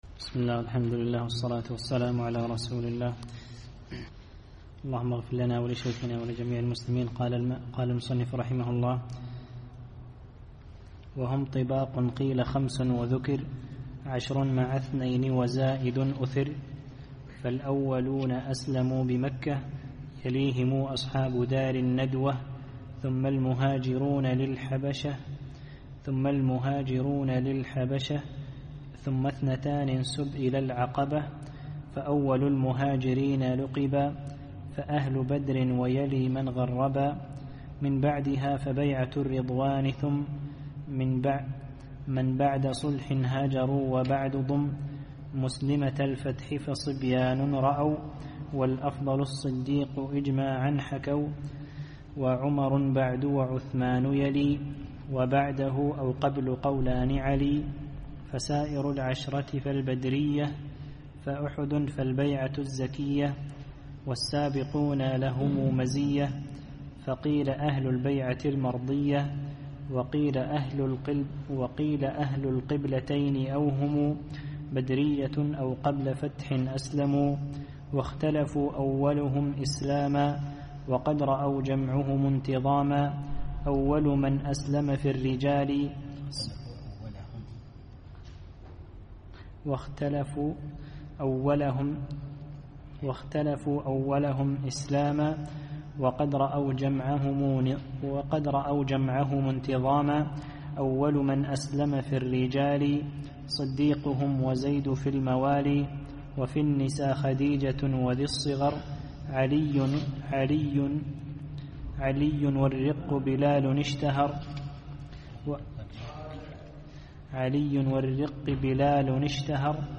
الدرس الحادي والثلاثون